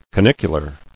[ca·nic·u·lar]